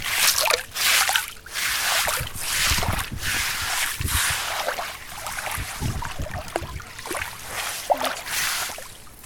젖은모래쓸어내기.mp3